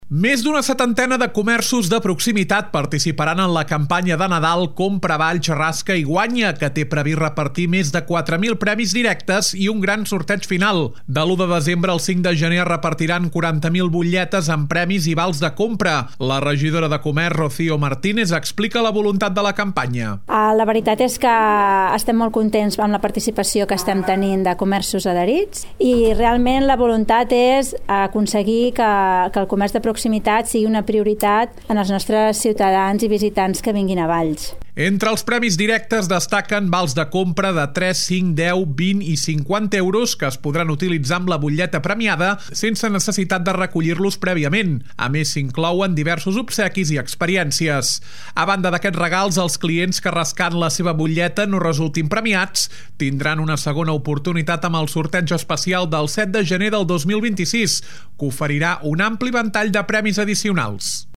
La regidora de Comerç, Rocío Martínez, explica la voluntat de la campanya.